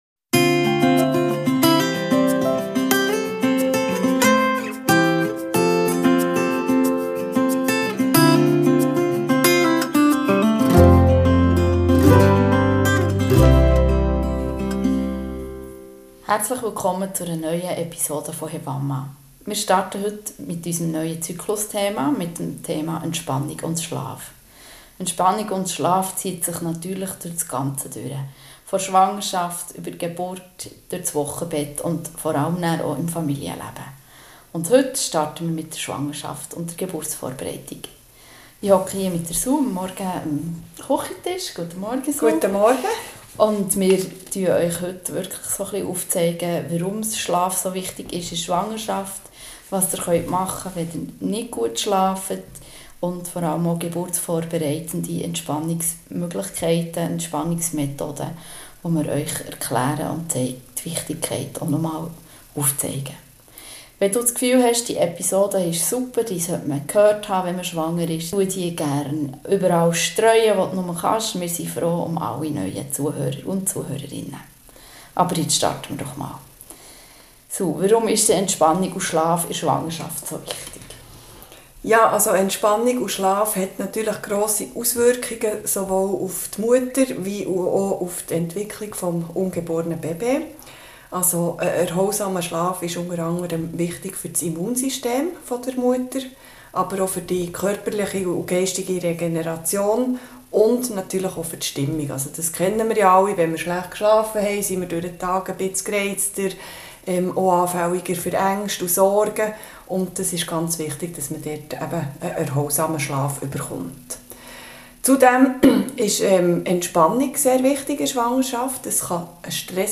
In dieser Folge setzen sich die beiden Hebammen am Küchentisch